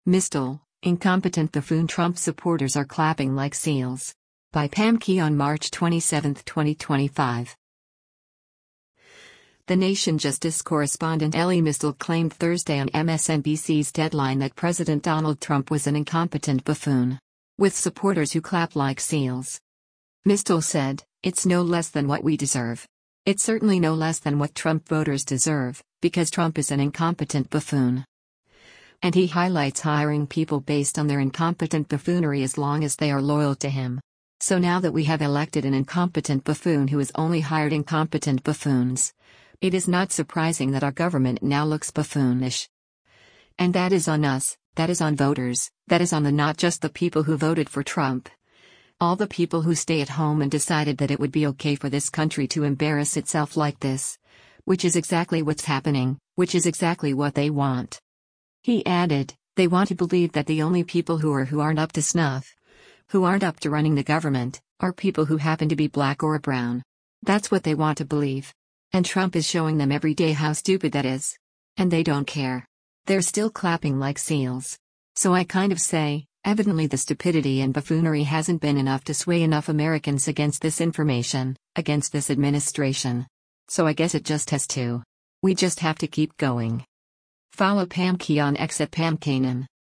The Nation justice correspondent Elie Mystal claimed Thursday on MSNBC’s “Deadline” that President Donald Trump was an “incompetent buffoon.” with supporters who clap like seals.